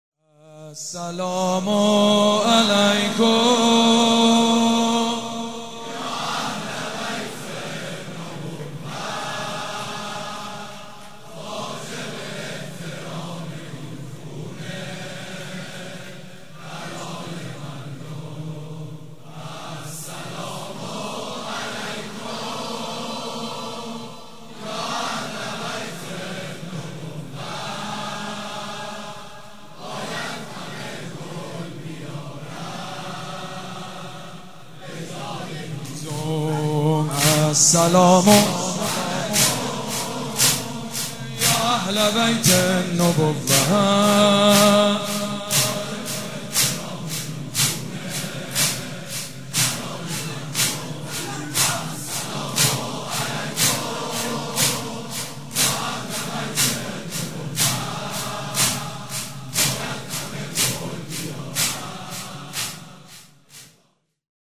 شب پنجم فاطميه دوم١٣٩٤
مداح
حاج سید مجید بنی فاطمه
مراسم عزاداری شب شهادت حضرت زهرا (س)